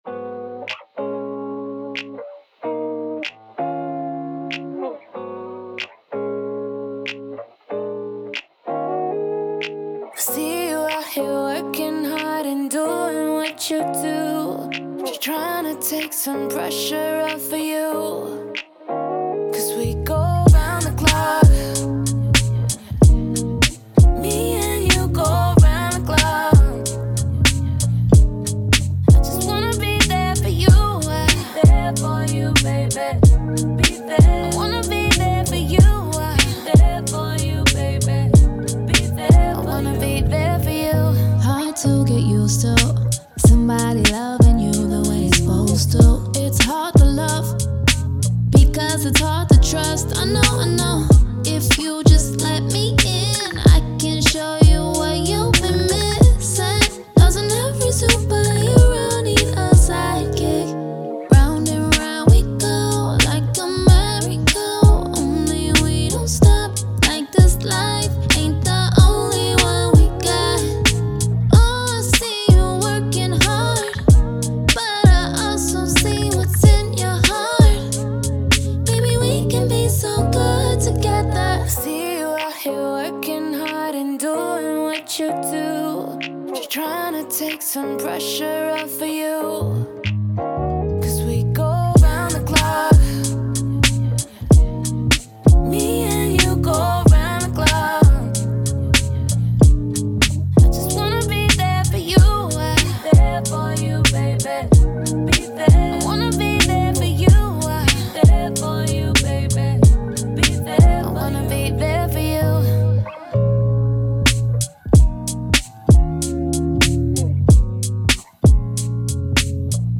Singer/Songwriter
Acoustic, R&B
Eb min